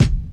'00s Mid-Range Hip-Hop Steel Kick Drum E Key 21.wav
Royality free bass drum sample tuned to the E note. Loudest frequency: 443Hz
00s-mid-range-hip-hop-steel-kick-drum-e-key-21-zlU.mp3